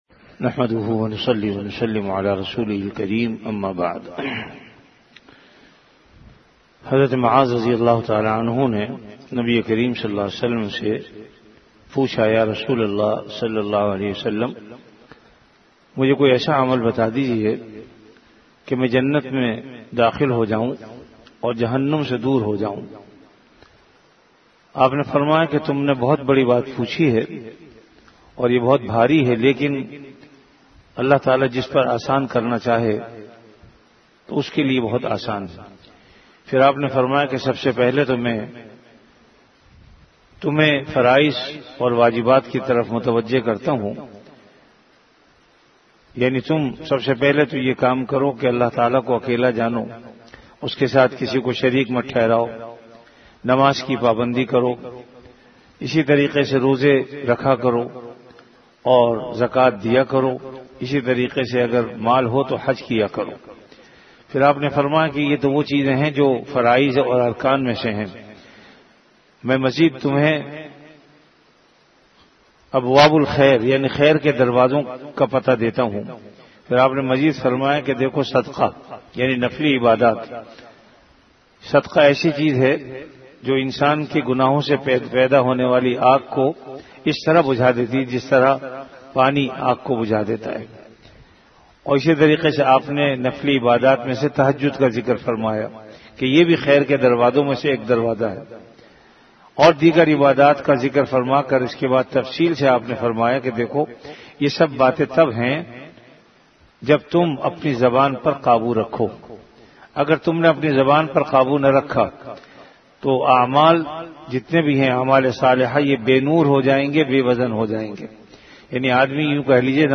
CategoryRamadan - Dars-e-Hadees
Event / TimeAfter Fajr Prayer